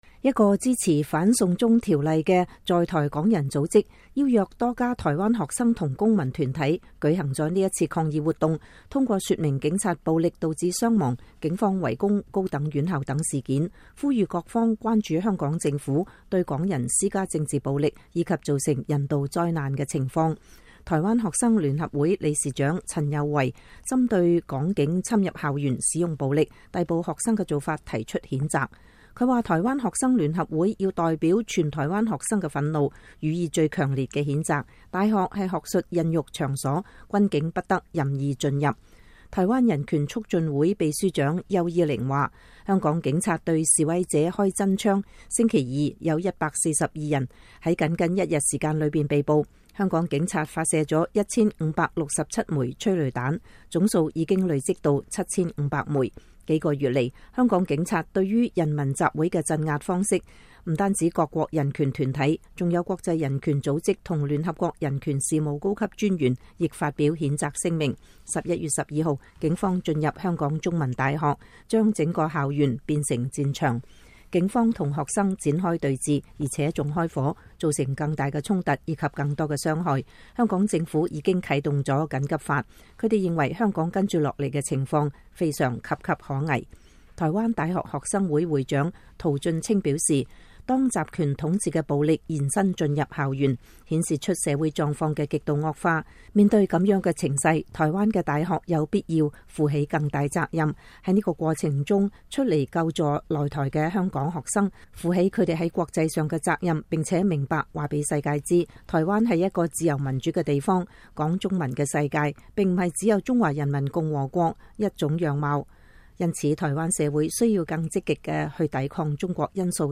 台灣十多個學生及公民團體星期五（11月15日）聚集在台北的香港經貿文化辦事處前舉行聯合記者會暨抗議香港警察校園暴力的說明會。